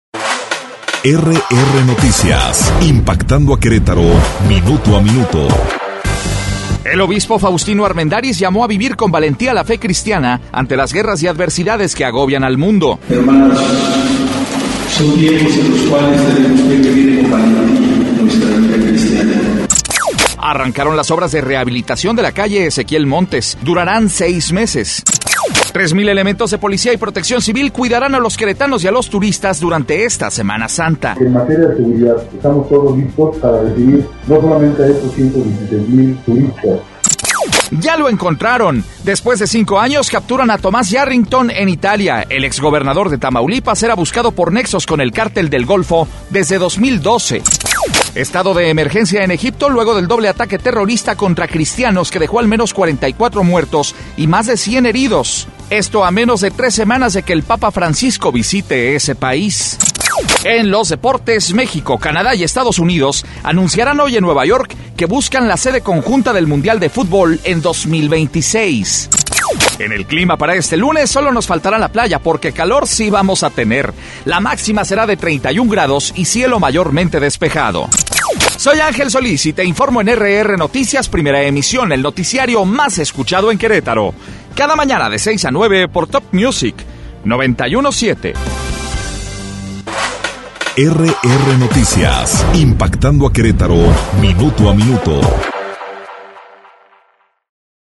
Resumen Informativo